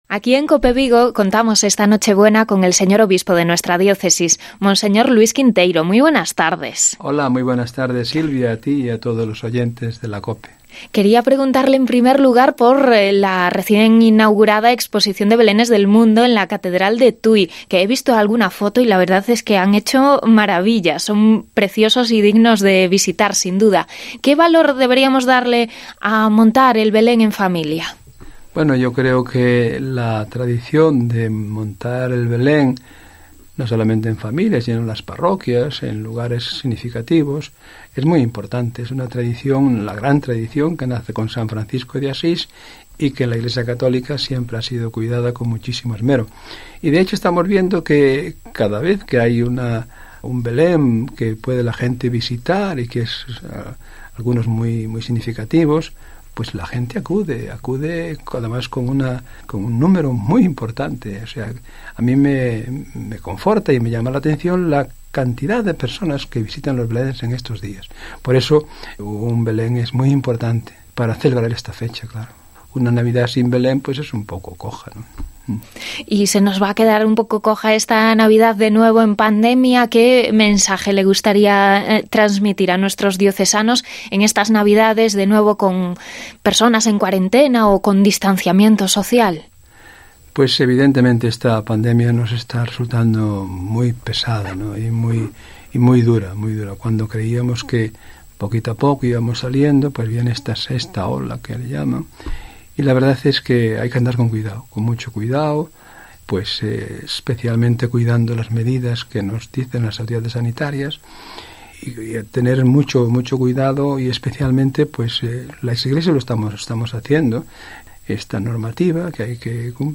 Entrevista por Nochebuena con monseñor Luis Quinteiro, obispo de Tui-Vigo